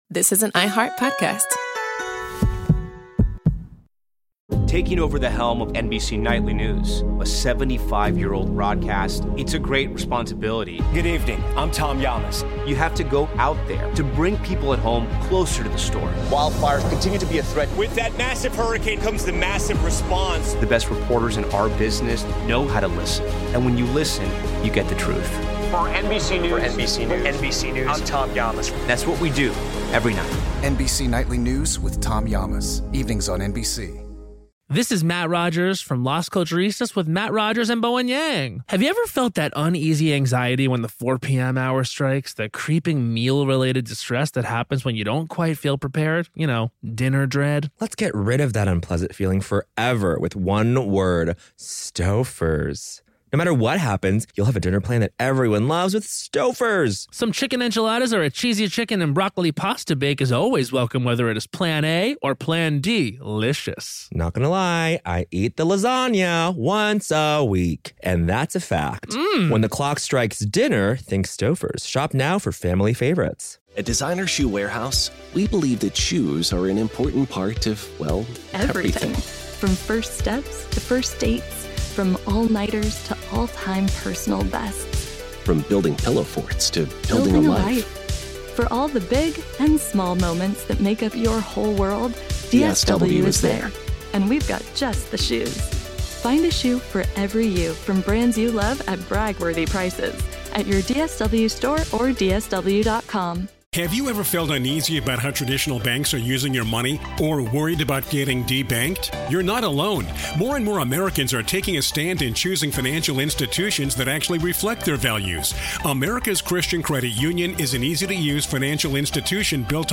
Special thanks to the U.S. National Archives for providing this audio, recorded during a 2010 roundtable discussion.